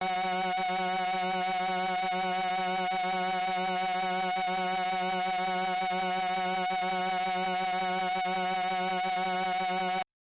标签： F4 MIDI - 注意-66 赤-AX80 合成器 单票据 多重采样
声道立体声